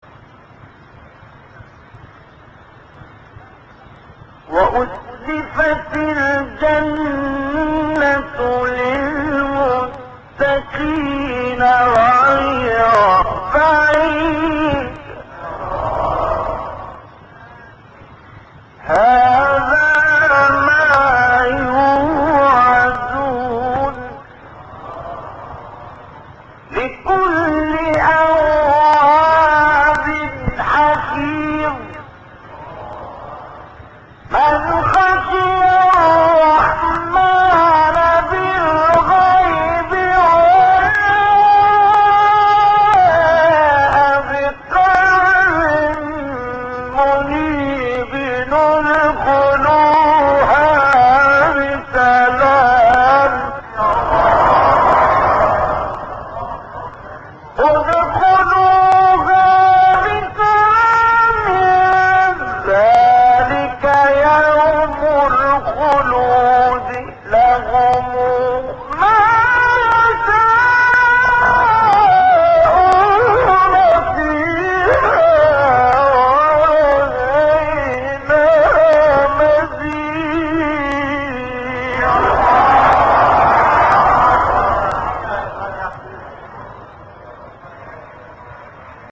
چهارگاه